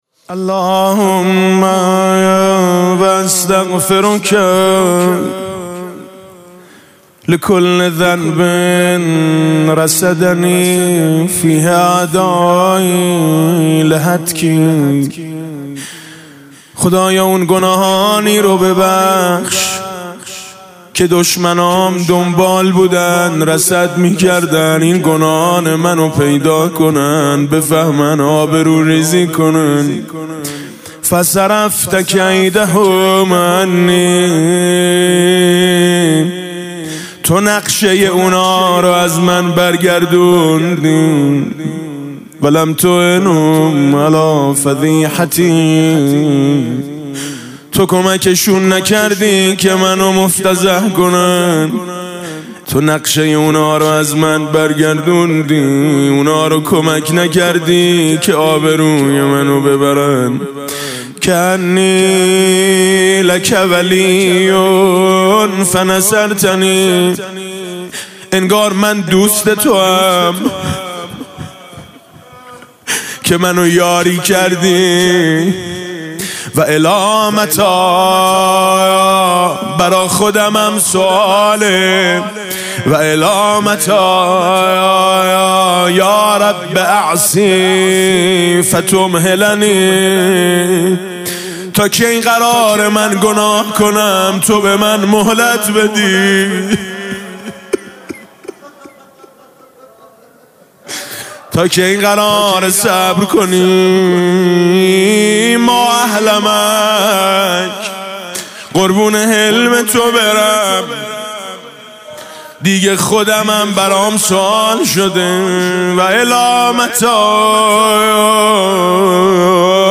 قرائت استغفار ۷۰ بندی حضرت امام علی علیه السلام با نوای دلنشین حاج میثم مطیعی